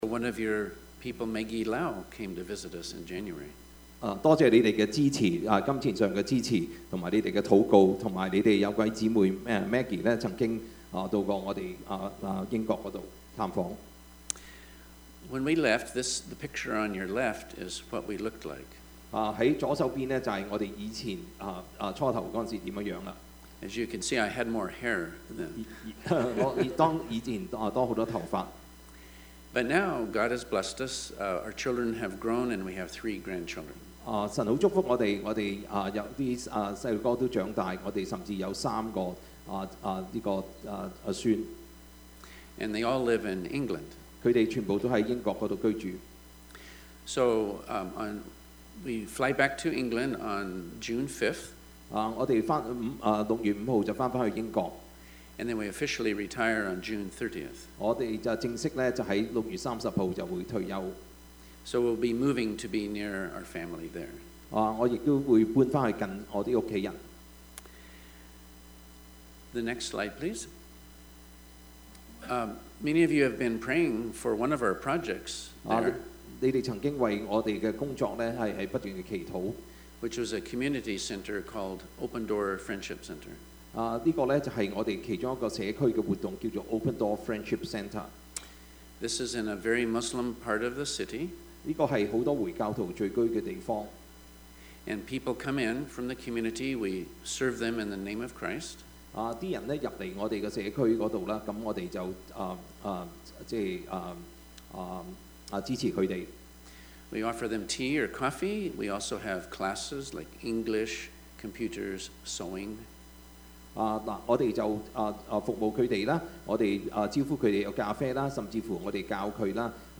Service Type: 主日崇拜
Topics: 主日證道 « 天父對孤兒的心意 行善行惡 »